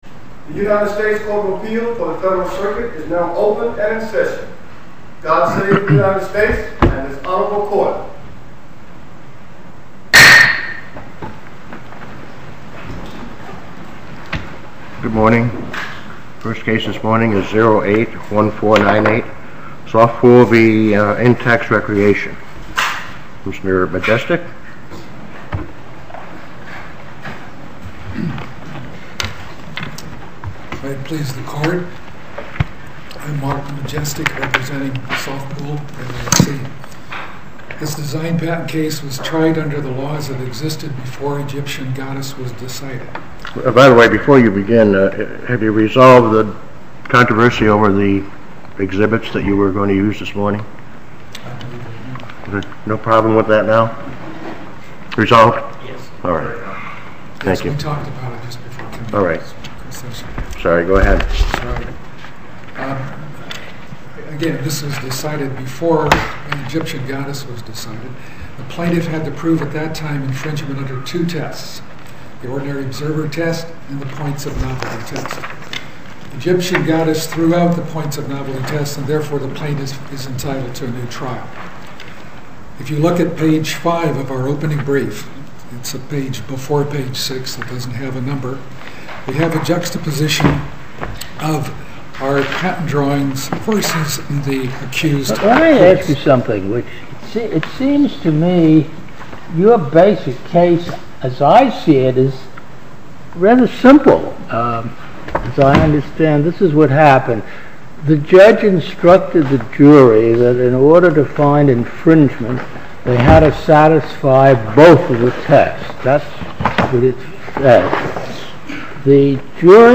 Oral argument audio posted: Sofpool v Intex Recreation (mp3) Appeal Number: 2008-1498 To listen to more oral argument recordings, follow this link: Listen To Oral Arguments.